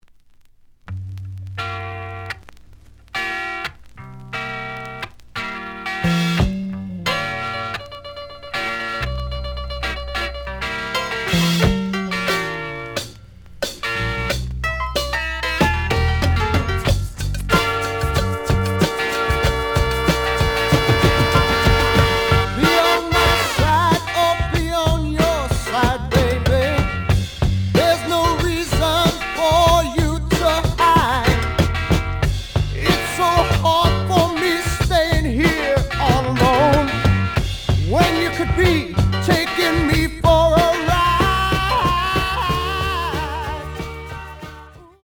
The audio sample is recorded from the actual item.
●Genre: Rock / Pop
Some damage on both side labels. Plays good.)